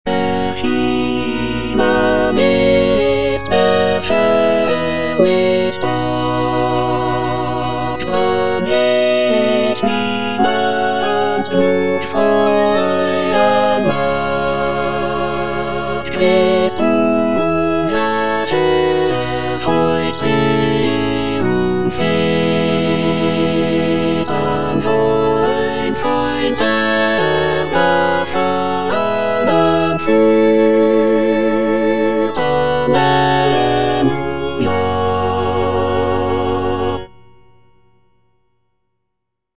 Choralplayer playing Cantata
The cantata is known for its intricate vocal and instrumental writing, as well as its rich harmonies and expressive melodies.